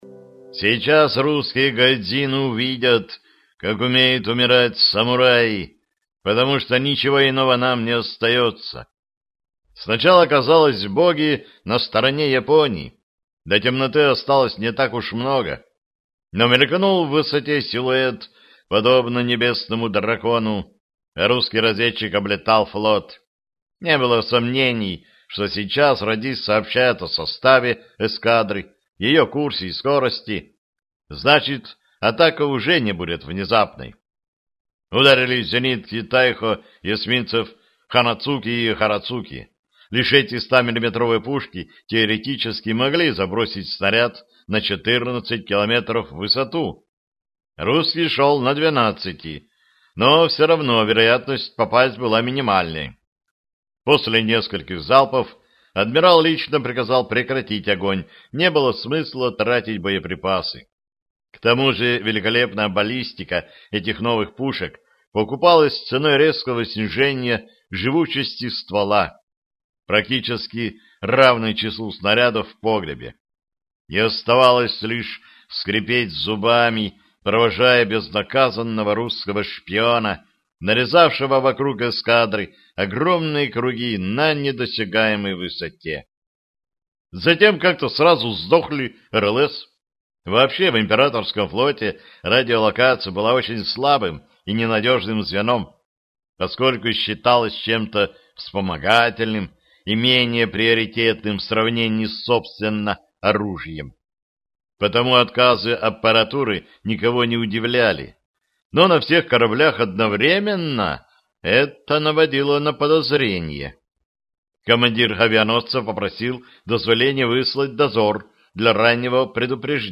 Aудиокнига Красный тайфун